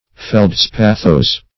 Search Result for " feldspathose" : The Collaborative International Dictionary of English v.0.48: Feldspathic \Feld*spath"ic\, Feldspathose \Feld*spath"ose\, a. Pertaining to, or consisting of, feldspar.
feldspathose.mp3